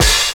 44 OP HAT.wav